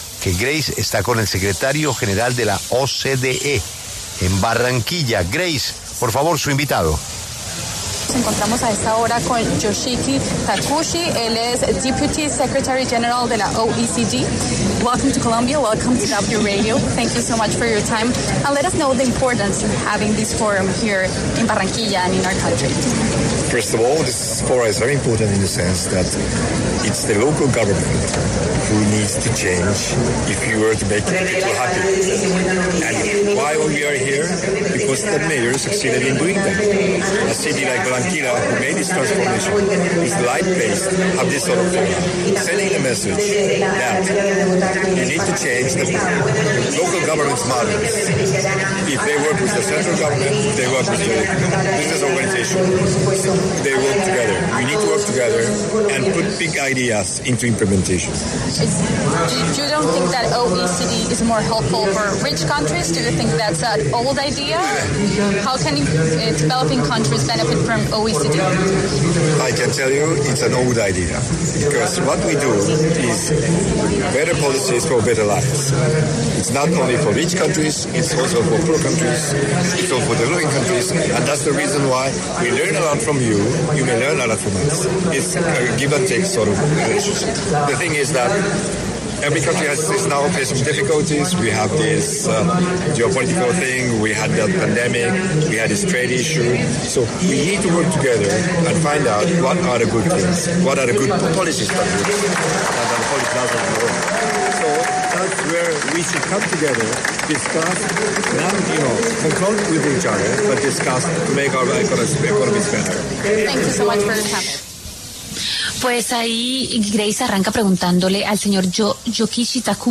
Yoshiki Takeuchi, secretario general adjunto de la OCDE, conversó con La W desde Barranquilla sobre el potencial de desarrollo que tiene Colombia.
Desde el lugar de la noticia, La W conversó con Yoshiki Takeuchi, secretario general adjunto de la OCDE, quien reflexionó sobre sus expectativas del foro y el papel del país en temas de desarrollo.